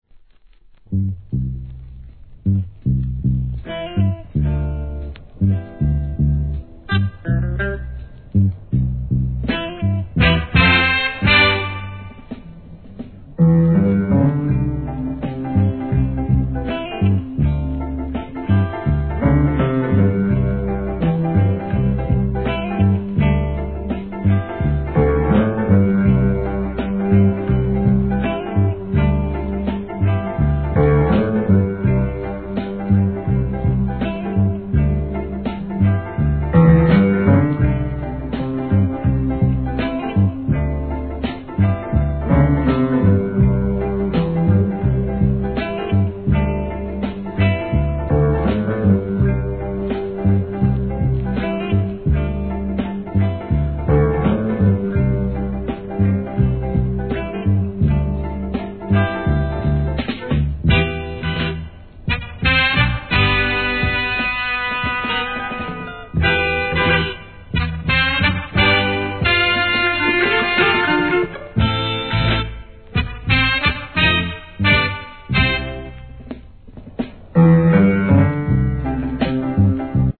REGGAE
極の展開もいい感じです♪ No. タイトル アーティスト 試聴 1.